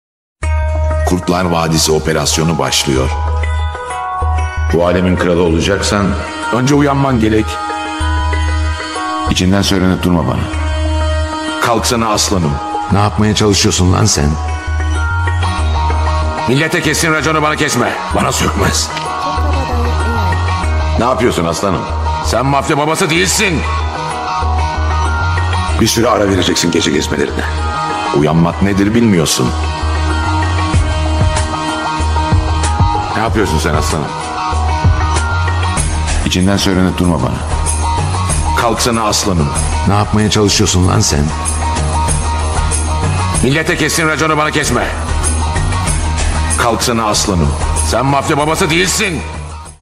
Aslan Akbey Alarm Sesi
Kategori: Zil Sesleri